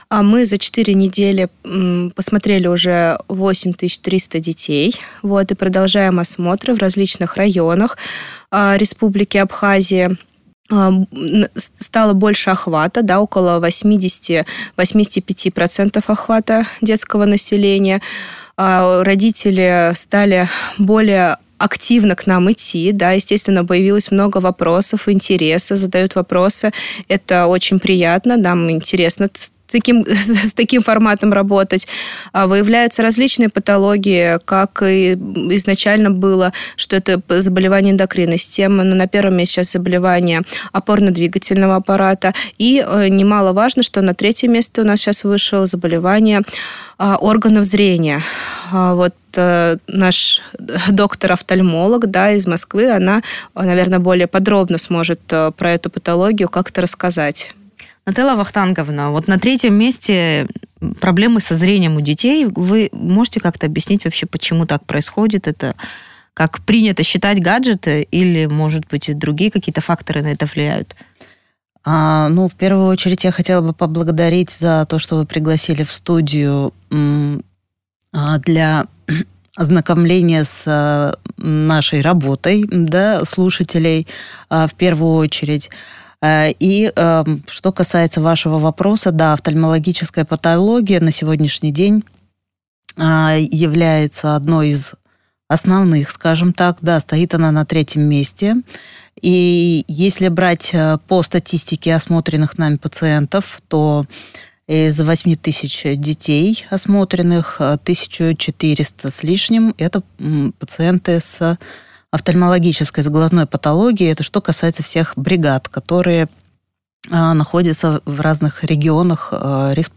Доктор-офтальмолог объяснила, какие патологии у детей выявлены и с чем это связано.